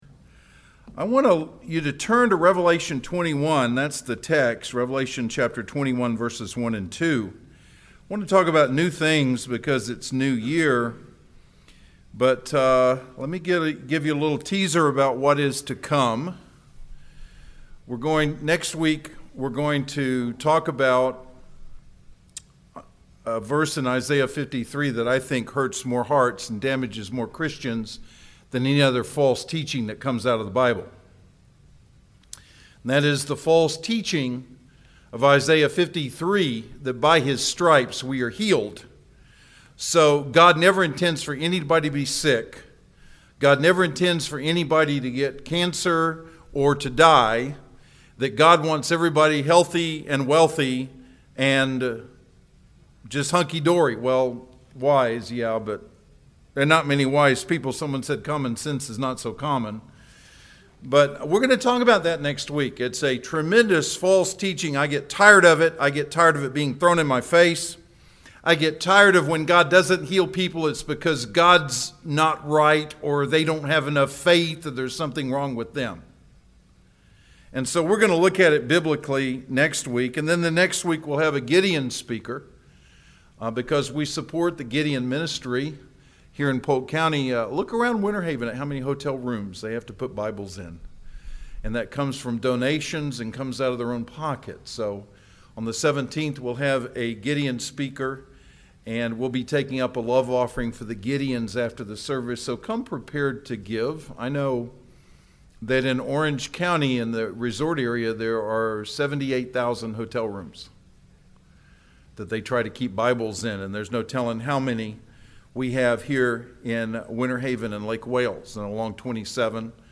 Inman Park Baptist Church SERMONS